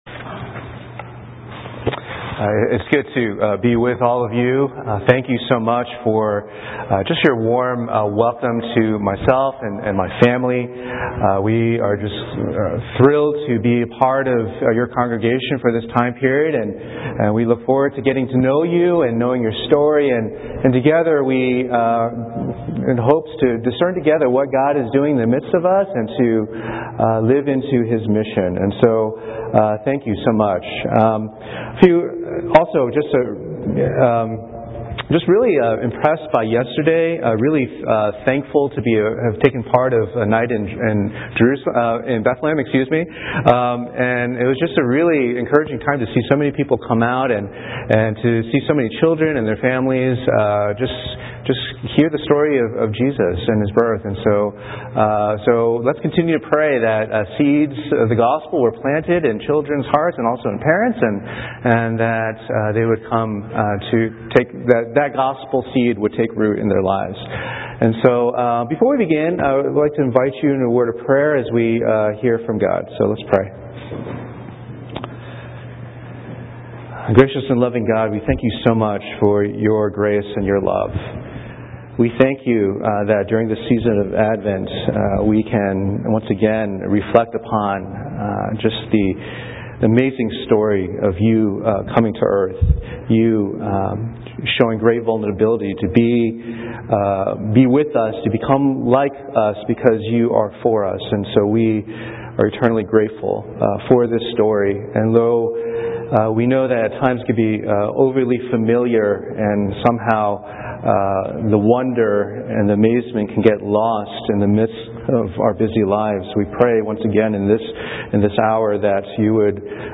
Sermons - Page 39 of 74 | Boston Chinese Evangelical Church